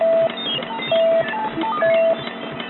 computer.mp3